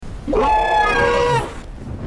Lizard